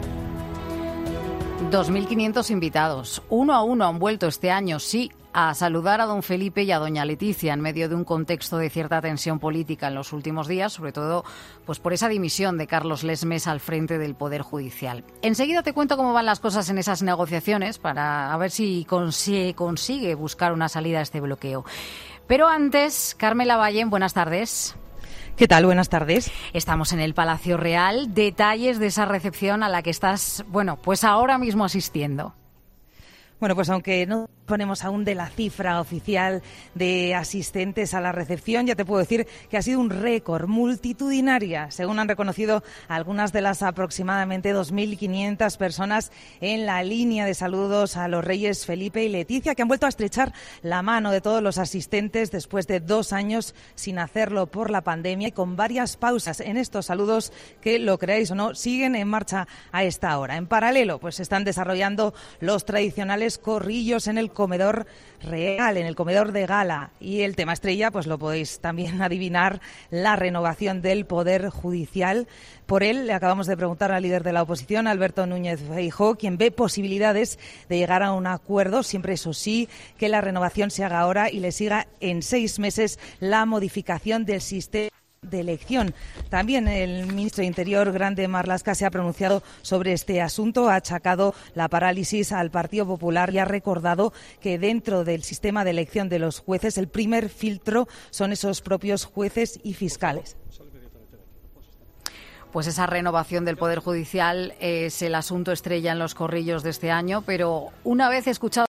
se encuentra en el Palacio Real para contar en COPE cómo sucede la recepción de los Reyes por el Día de la Fiesta Nacional